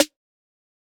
SouthSide Snare (14).wav